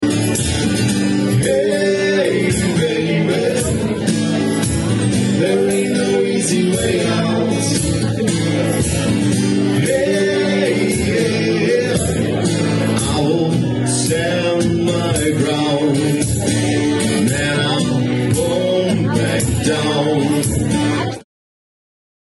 Akustische Rock- & Popmusik mit Leidenschaft
mal leise und gefühlvoll, mal kraftvoll und tanzbar
Gesang & Percussion
Gitarre & Gesang